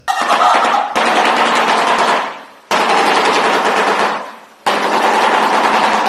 Shoebill.mp3